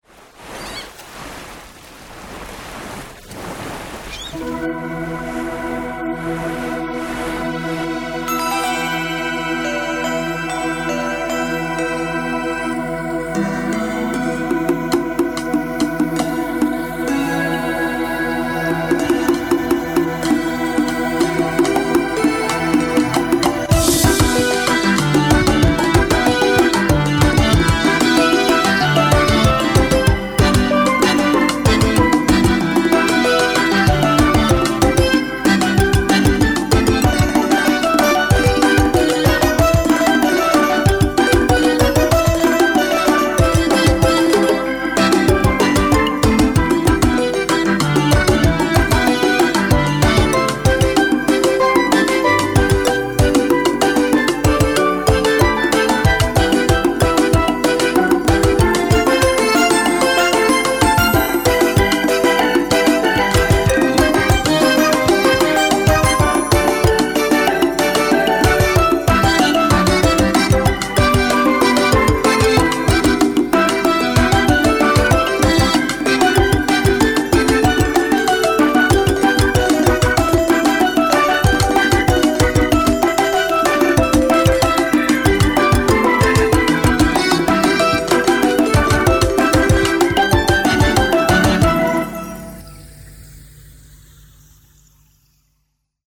Roland Xp50